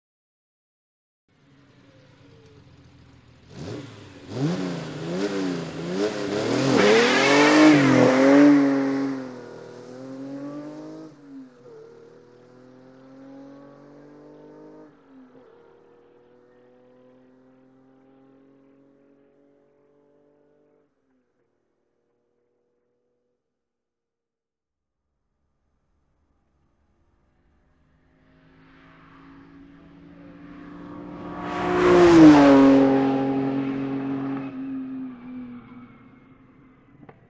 Ferrari 250 GTO -62 till exempel... bland det formskönaste och mest skönsjungande som producerats.